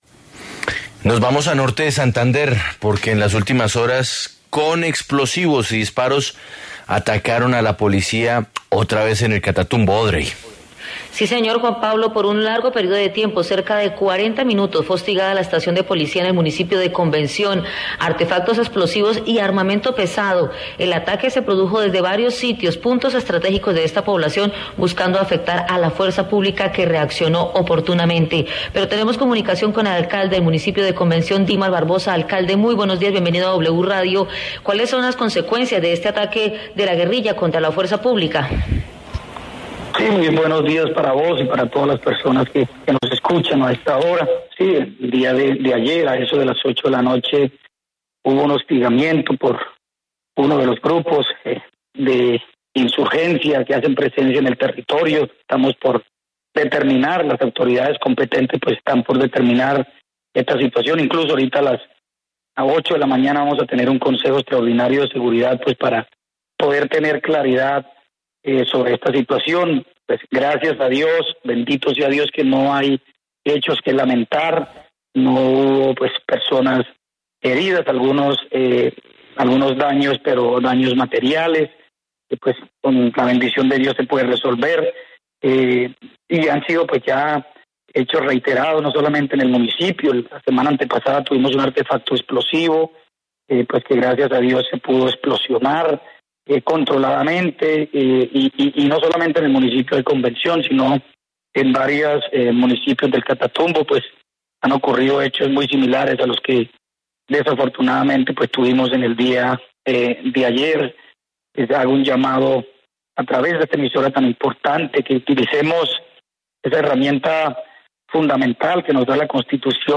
En diálogo con La W, el alcalde Dimar Barbosa se pronunció sobre este atentado contra la Policía que preocupa a la población.